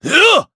Lusikiel-Vox_Attack4_jp.wav